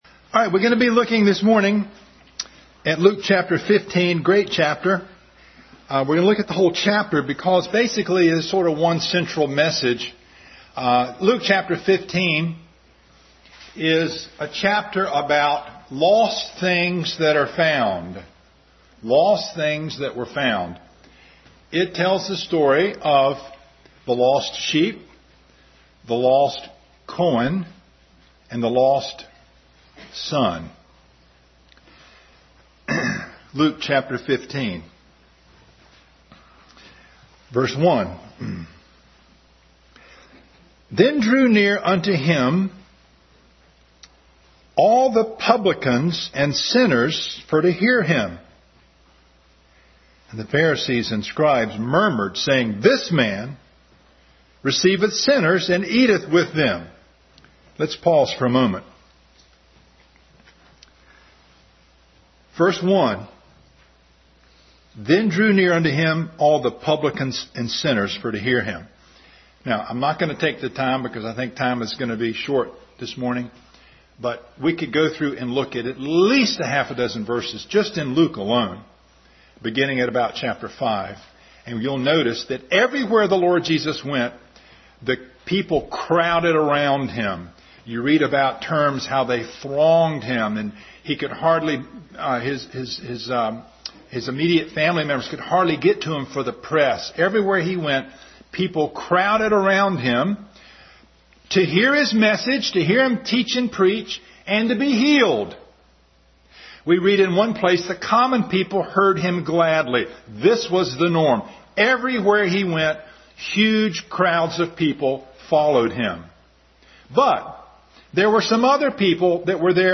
Luke 15 Service Type: Family Bible Hour Bible Text